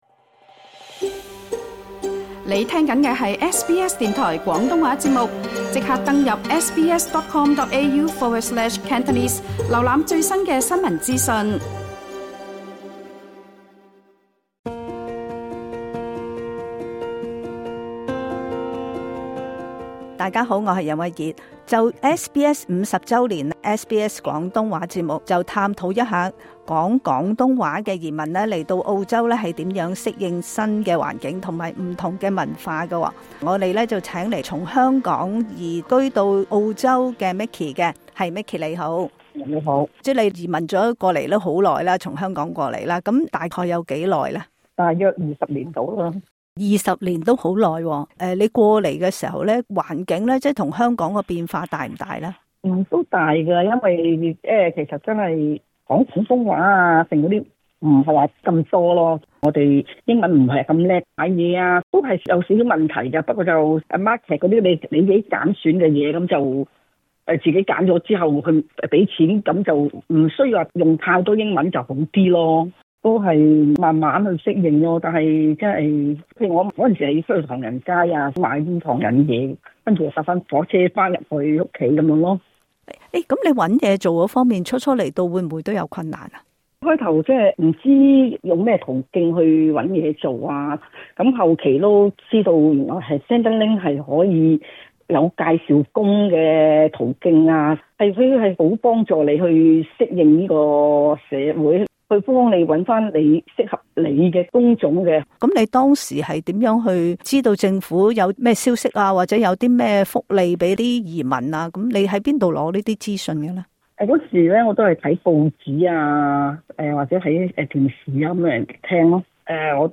初來澳洲時你是否經歷過不適應，甚至文化衝擊？慶祝SBS 50周年之際，兩位講廣東話的「老移民」受邀分享當年來到澳洲如何適應新環境與不同的文化。